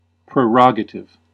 Ääntäminen
Vaihtoehtoiset kirjoitusmuodot (vanhentunut) prærogative Synonyymit privilege Ääntäminen US : IPA : [pɹə.ˈɹɑɡ.ə.tɪv] UK : IPA : /pɹɪ.ˈɹɒɡ.ə.tɪv/ Lyhenteet ja supistumat (laki) Prerog.